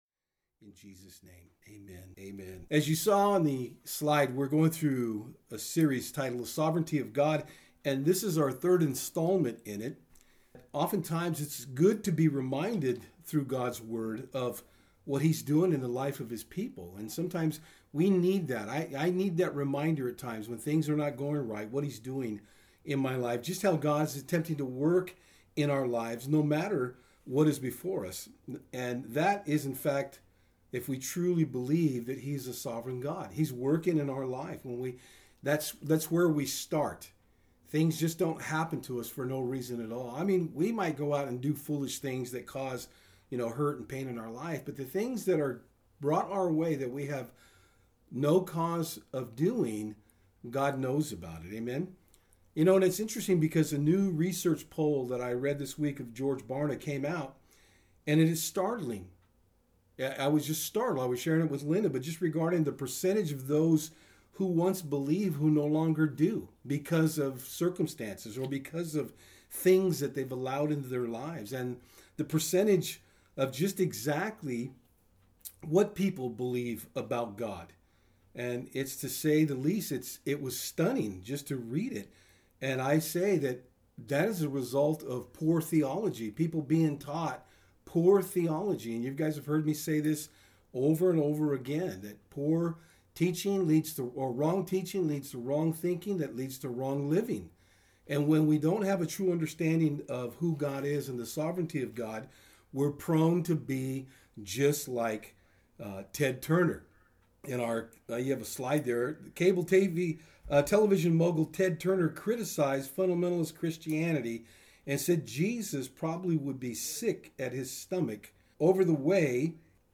Service Type: Sundays @ Fort Hill